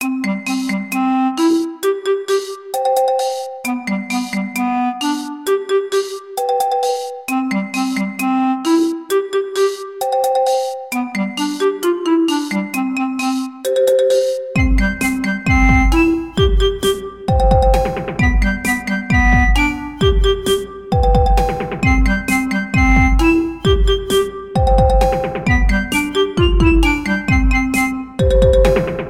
クラリネットとマリンバがコミカルで愉快なメロディーを奏でます。
ショートループ